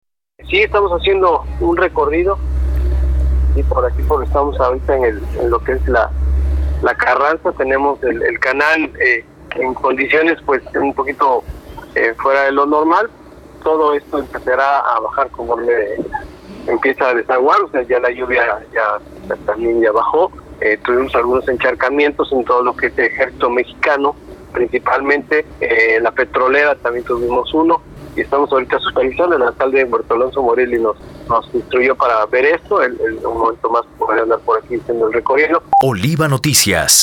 Veracruz, Ver.- La mañana de este jueves, una fuerte lluvia ocasionó encharcamientos fuertes a severos en algunos puntos de la zona conurbada, afectando momentáneamente el tráfico vehicular, confirmó el director de Protección Civil boqueño, Andrés Escalera Pavón, dijo que avenidas como Ejército Mexicano y partes de Díaz Mirón fueron las primeras en presentar anegaciones, sin embargo, no se reporten personas o viviendas afectadas.